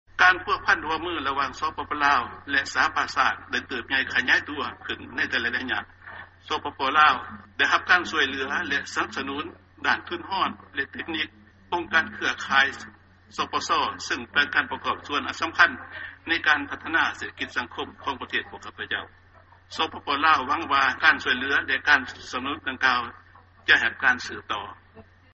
ຟັງຖະແຫລງການ ປະທານປະເທດ ຈູມມະລີ ໄຊຍະສອນ 9